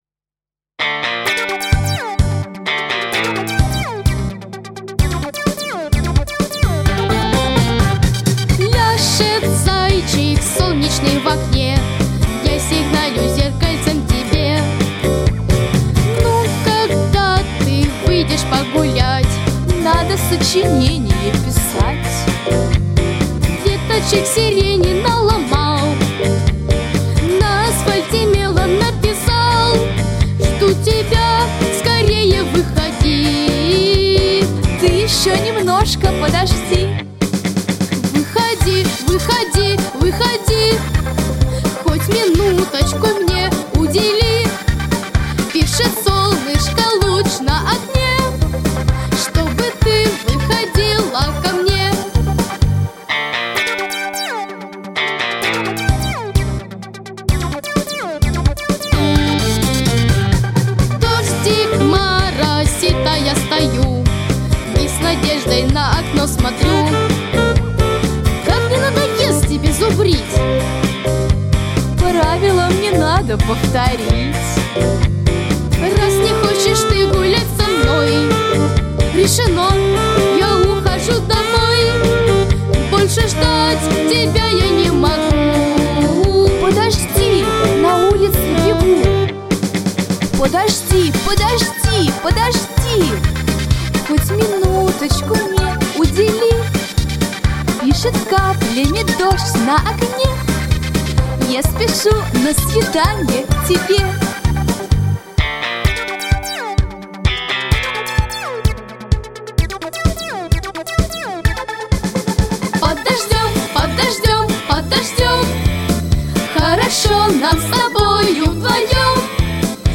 Детская песня
Детские песни